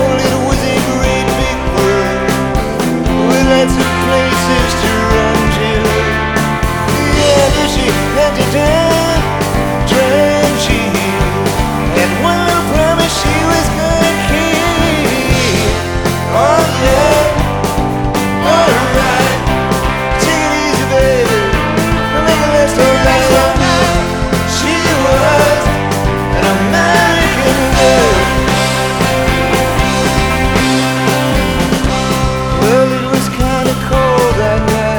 American Trad Rock Arena Rock
Жанр: Рок